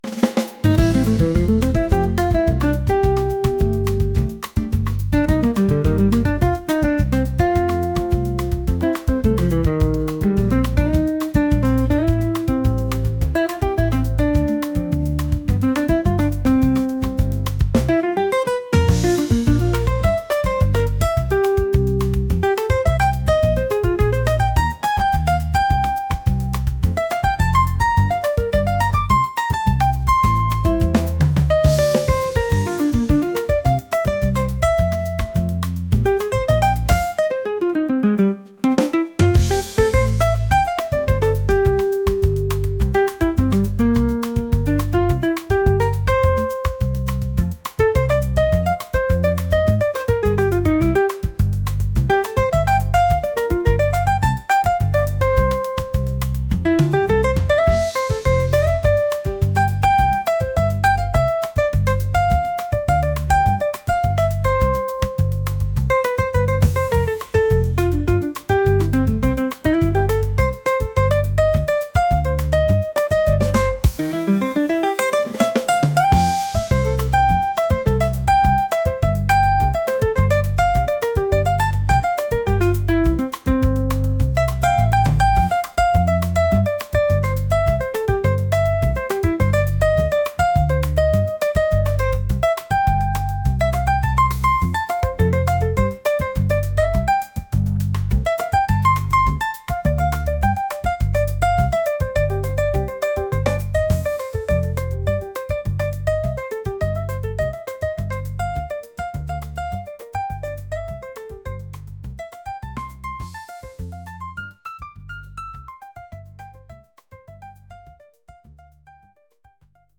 upbeat | jazz